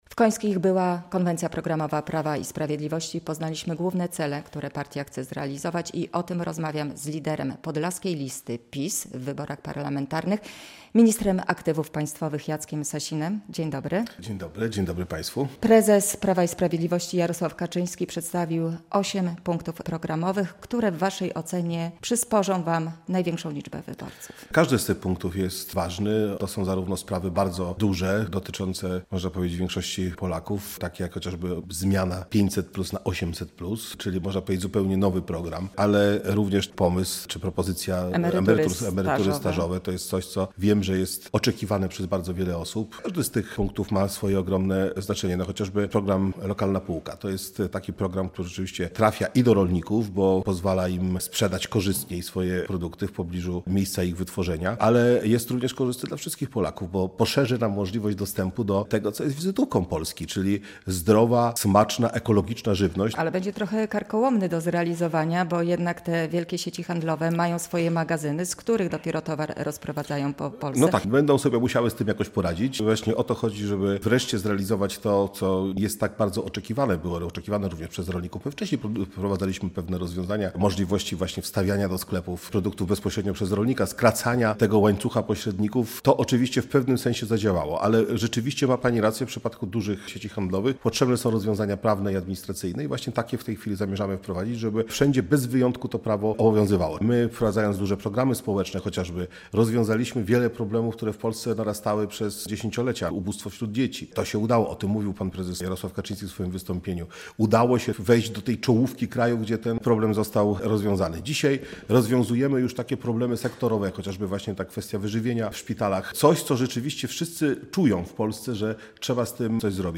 Radio Białystok | Gość | Jacek Sasin [wideo] - minister aktywów państwowych, lider podlaskiej listy PiS do Sejmu